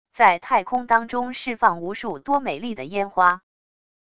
在太空当中释放无数多美丽的烟花wav音频